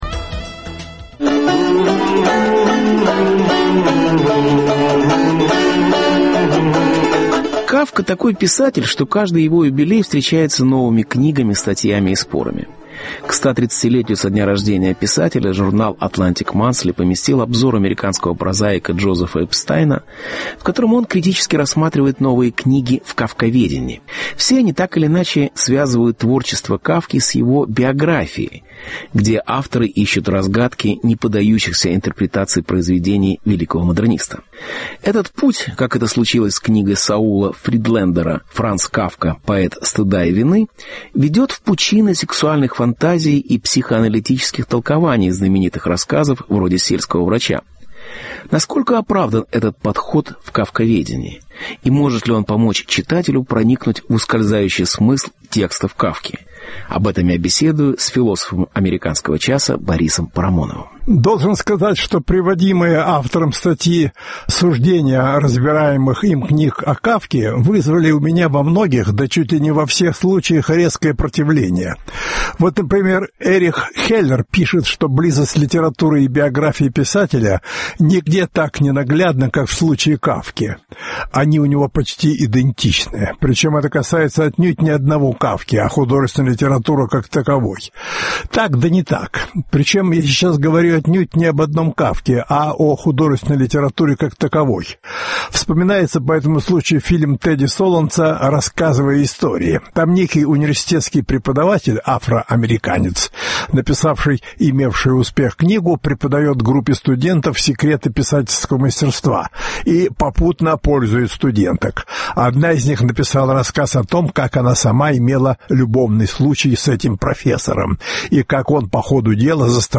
Кафка - сегодня и всегда Беседа с Борисом Парамоновым